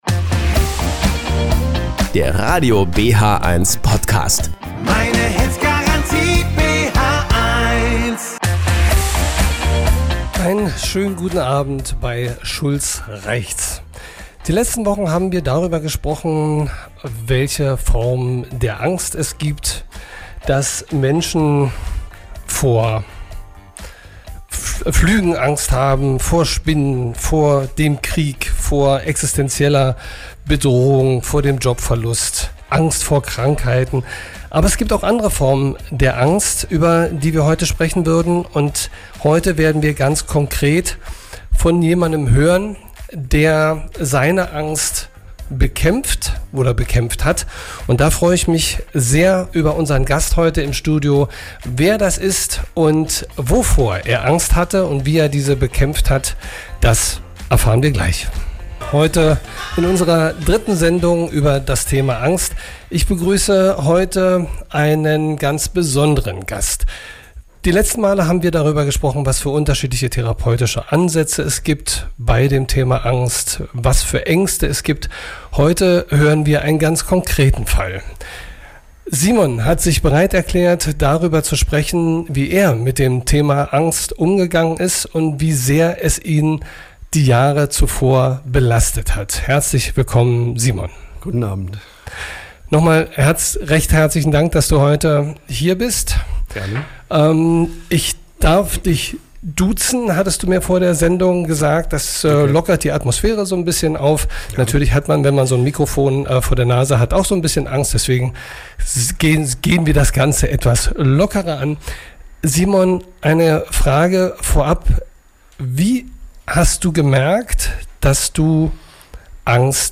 Diesmal berichtet ein Angstpatient von seinen Erfahrungen und Therapieerlebnissen.